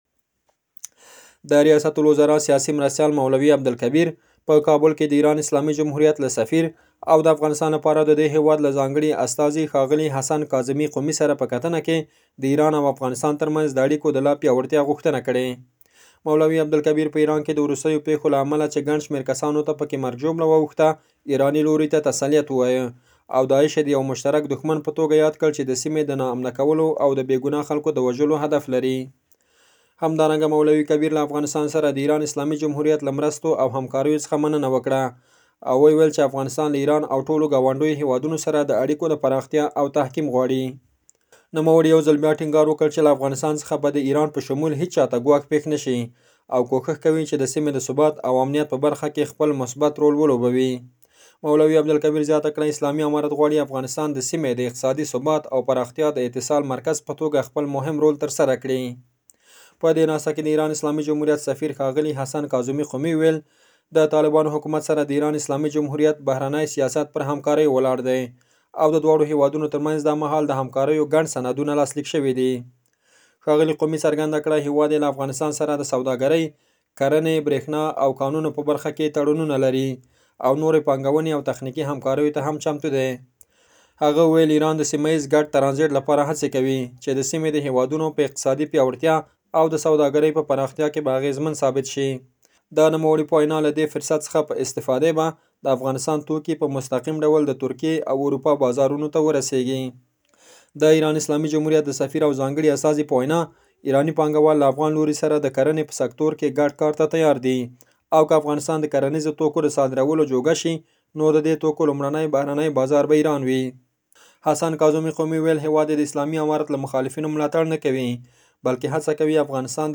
زموږ خبریال راپور راکړی، د افغانستان د رياست الوزرا سياسي معاونيت داعش د ايران او افغانستان مشترک دښمن بللی چې موخه یې د سیمې ناامنه کول او د بې ګناه خلکو...